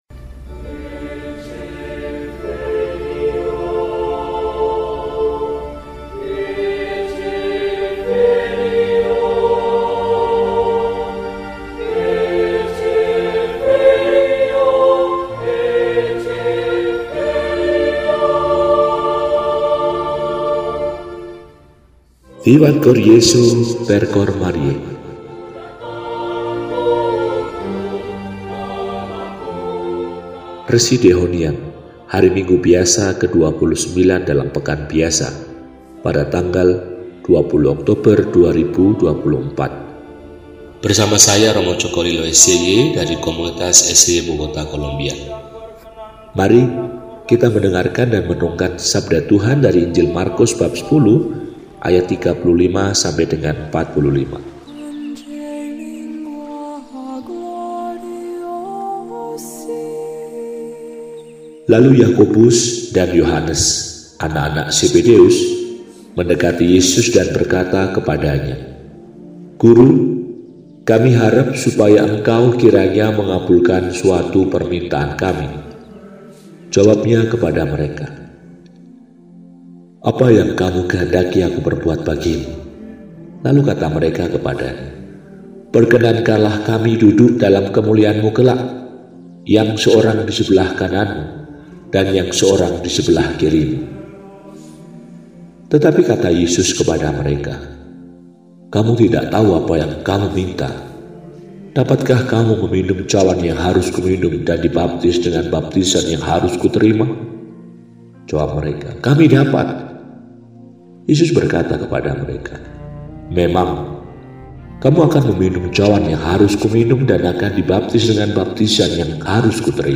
Minggu, 20 Oktober 2024 – Hari Minggu Biasa XXIX – Hari Minggu Misi Sedunia ke-98 – RESI (Renungan Singkat) DEHONIAN